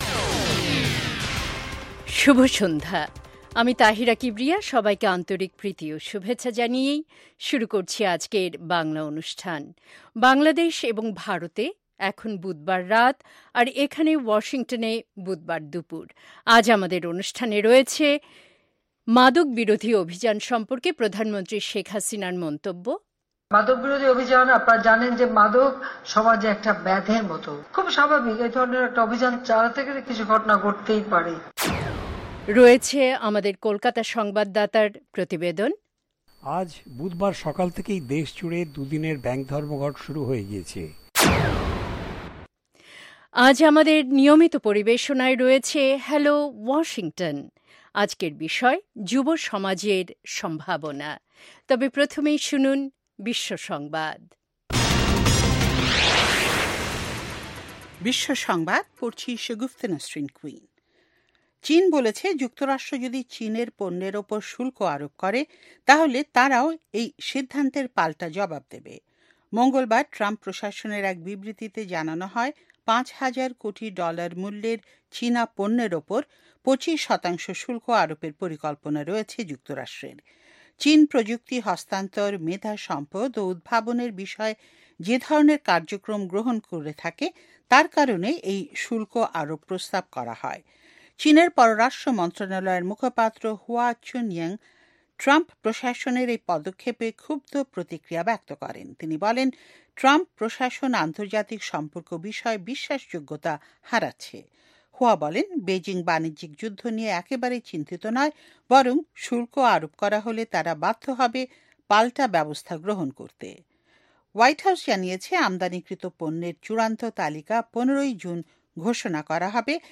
অনুষ্ঠানের শুরুতেই রয়েছে আন্তর্জাতিক খবরসহ আমাদের ঢাকা এবং কলকাতা সংবাদদাতাদের রিপোর্ট সম্বলিত বিশ্ব সংবাদ, বুধবারের বিশেষ আয়োজন হ্যালো ওয়াশিংটন। আর আমাদের অনুষ্ঠানের শেষ পর্বে রয়েছে যথারীতি সংক্ষিপ্ত সংস্করণে বিশ্ব সংবাদ।